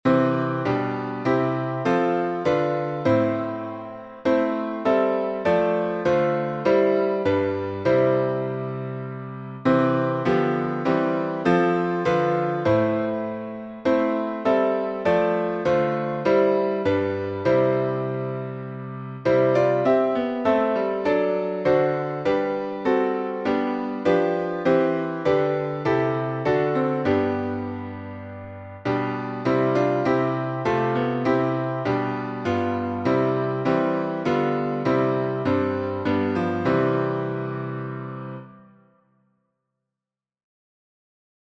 #2017: All Glory, Laud, and Honor — Alternate four stanzas, in C Major | Mobile Hymns